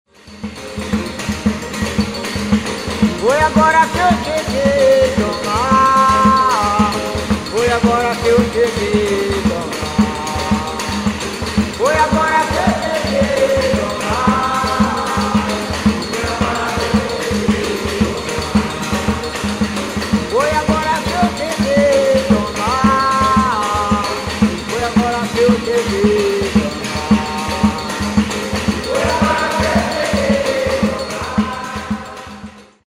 Samba de roda (atividade musical)
Samba de ritmo dançante que tem sua melodia executada por um solista e respondida pelo coro composto pelos participantes dispostos em roda. Sua formação instrumental inclui viola, violão, cavaquinho, timbaus, pandeiros, palmas e prato cuja borda é raspada com uma colher. Praticado no recôncavo baiano.
sambaderoda.mp3